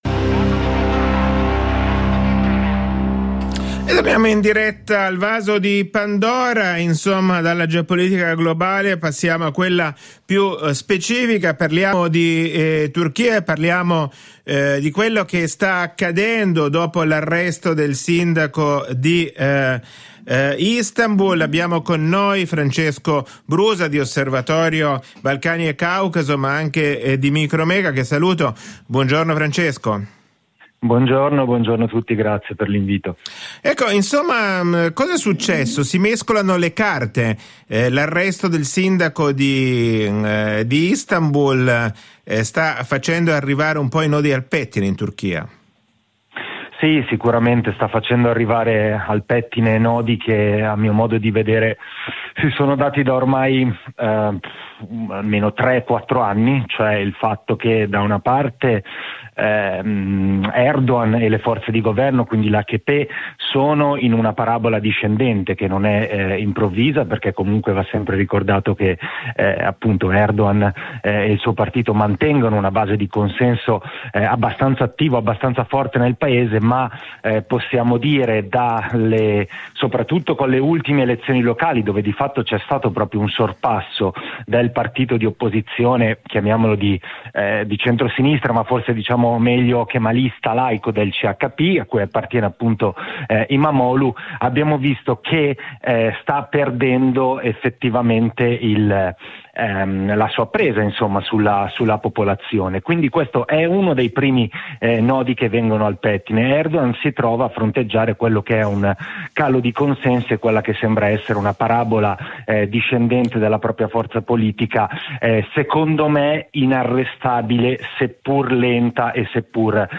alla trasmissione "Il Vaso di Pandora" (4 aprile 2025)